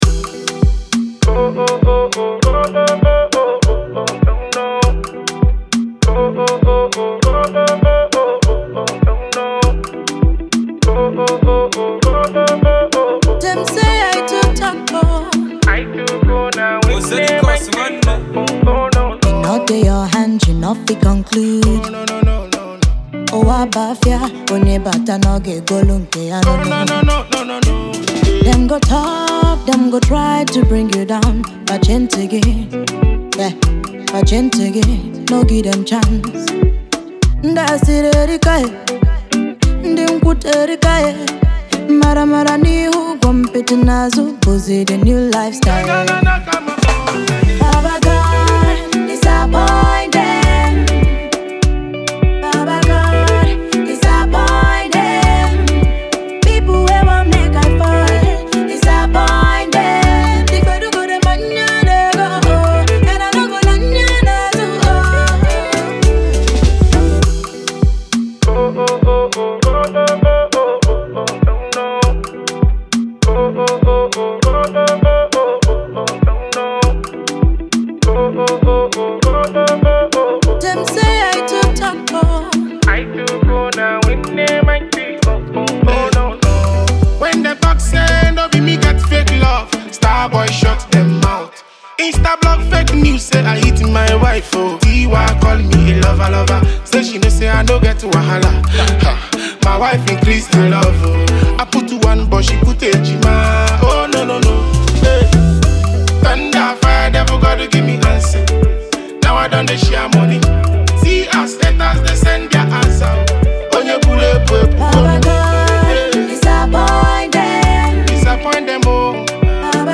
Afro-pop
Nigerian Singer